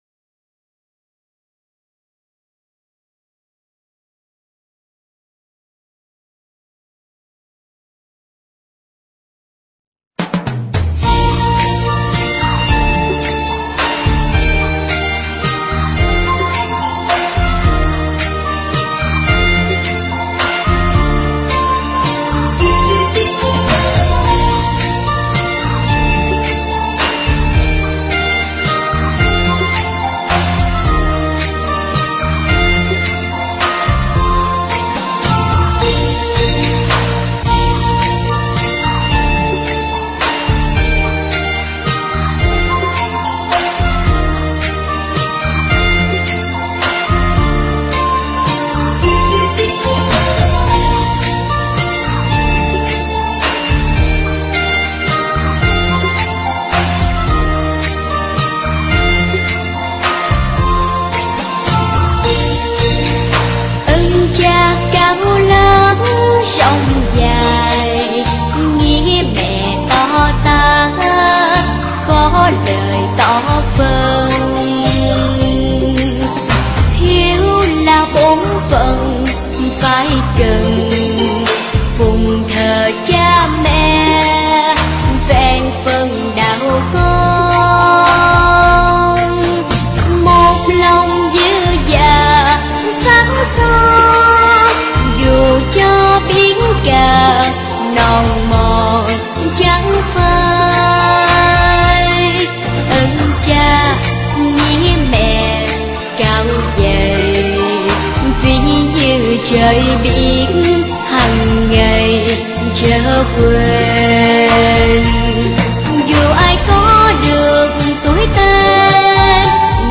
Mp3 thuyết pháp